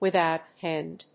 withòut énd